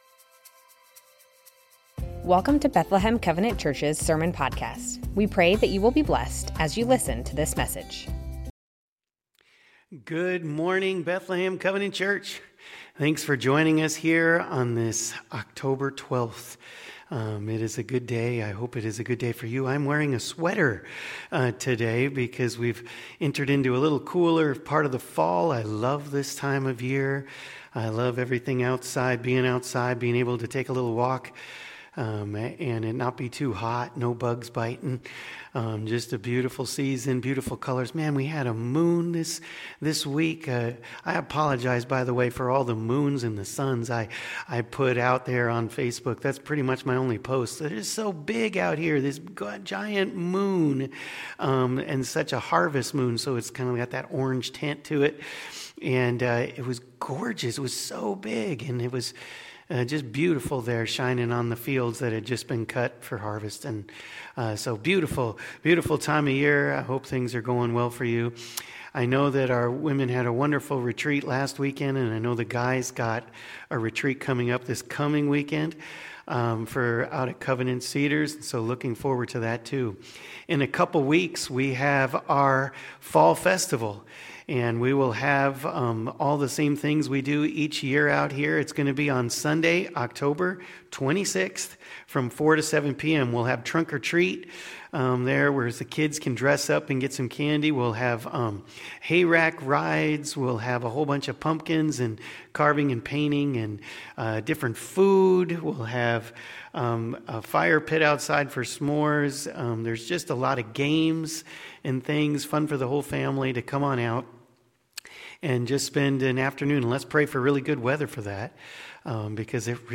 Bethlehem Covenant Church Sermons The Names of God - Jehovah Rohi Oct 12 2025 | 00:33:54 1x 00:00 / 00:33:54 Subscribe Share Spotify RSS Feed Share Link Embed